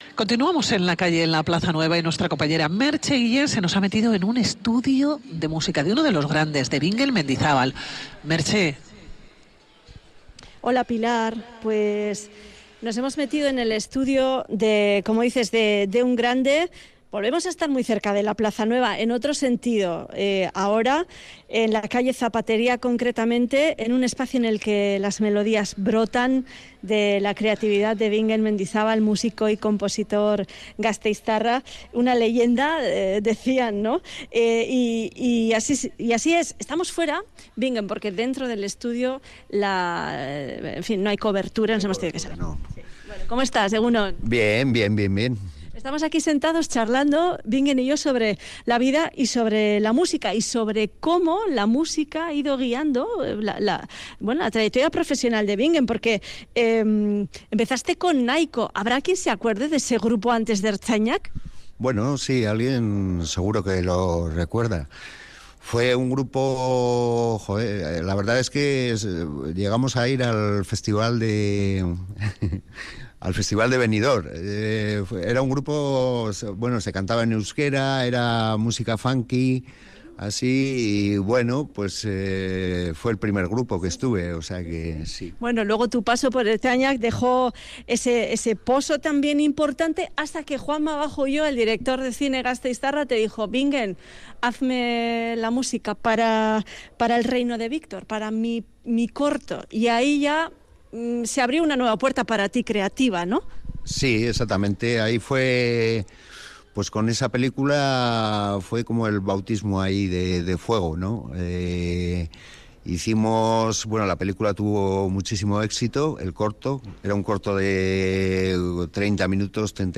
Tras el éxito de 'Baby', nos abre las puertas de su estudio en pleno Casco Viejo gasteiztarra.